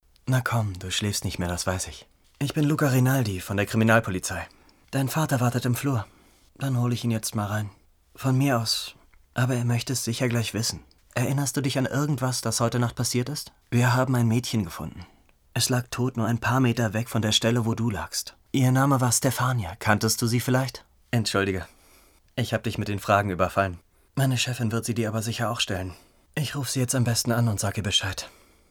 ★ Rolle: Luca Rinaldi